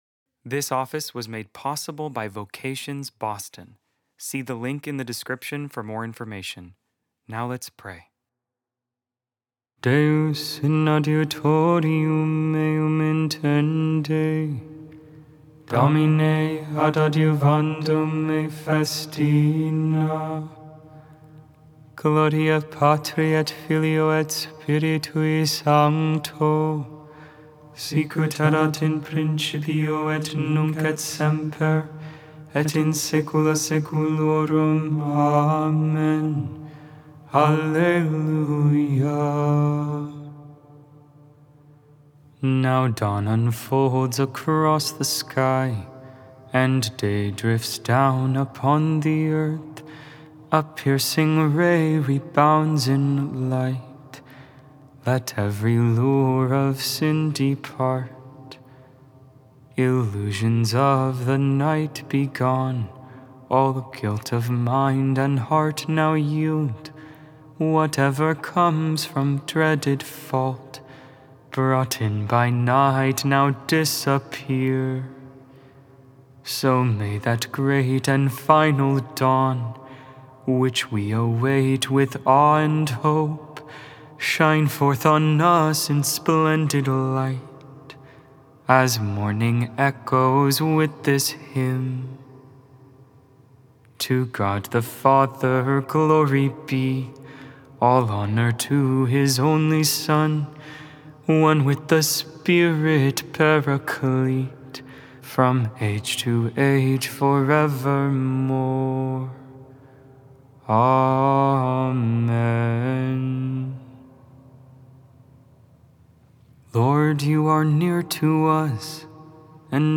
Lauds, Saturday Morning Prayer on the 27th Saturday in Ordinary Time, October 11, 2025.Made without AI. 100% human vocals, 100% real prayer.
antiphon StH, Gregorian tone VII
Solesmes reading tone